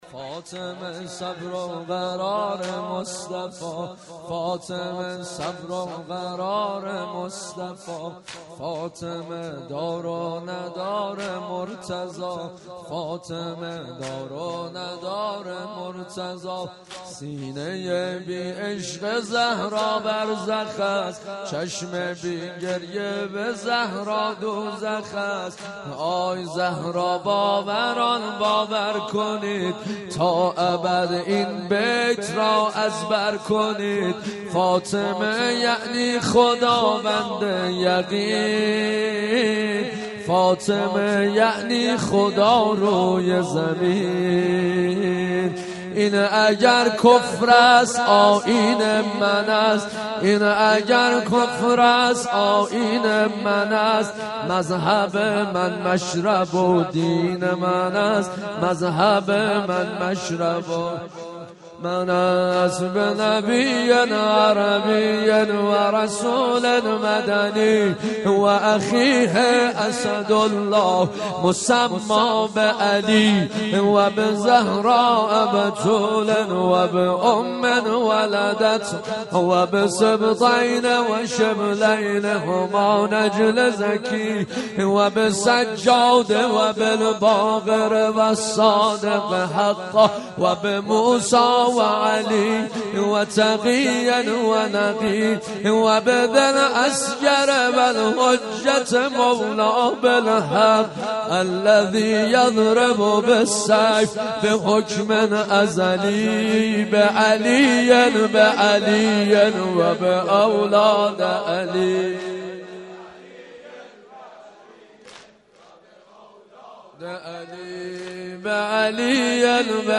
سینه زنی در شهادت بی بی دوعالم حضرت زهرا(س